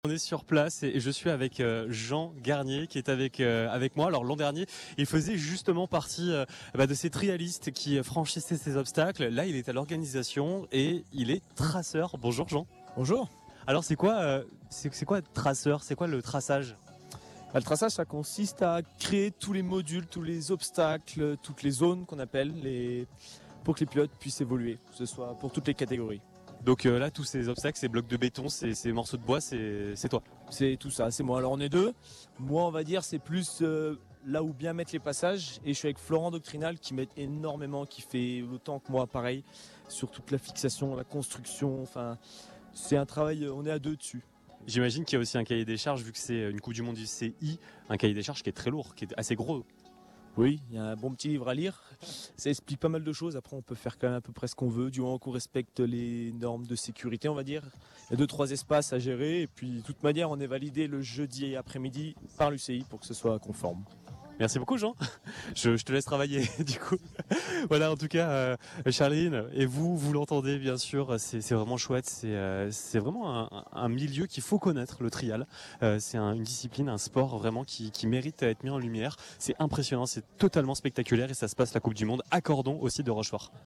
À l’occasion de la Coupe du Monde de VTT Trial, nous étions en direct de Cordon pour une émission spéciale en direct du cœur de l’événement.
Interview